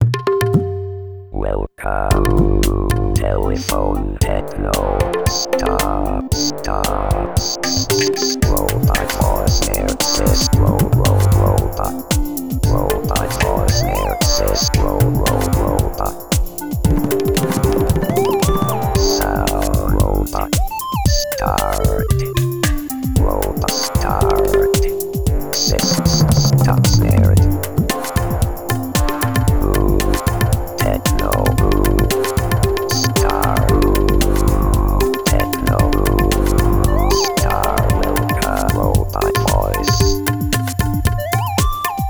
Tecno robot (bucle)
melodía
repetitivo
ritmo
robot
sintetizador